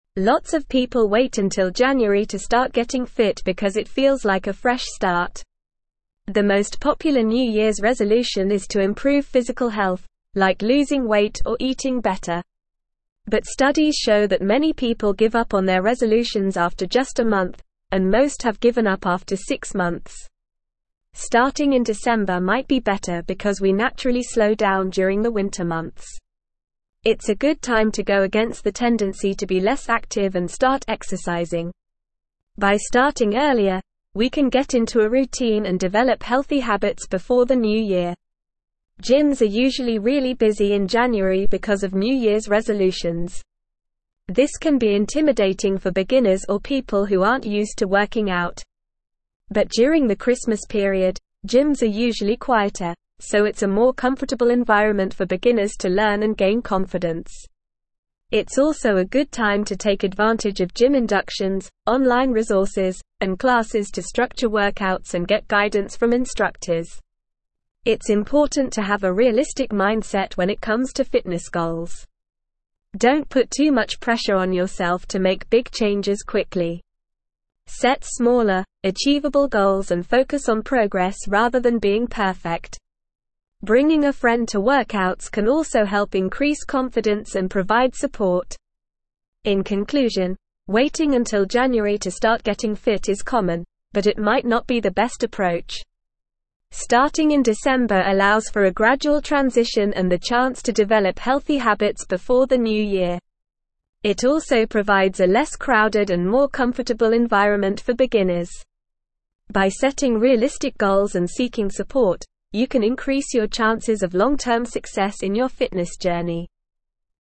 Normal
English-Newsroom-Upper-Intermediate-NORMAL-Reading-Starting-Your-Fitness-Journey-Why-December-is-Ideal.mp3